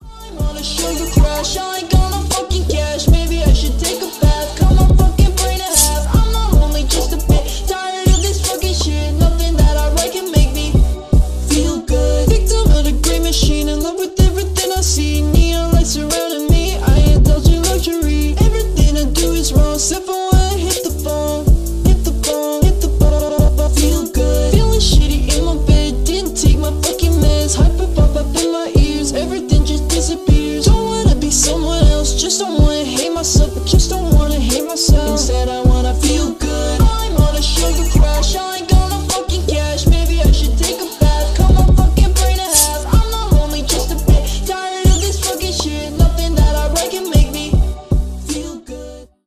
бесплатный рингтон в виде самого яркого фрагмента из песни
Поп Музыка
весёлые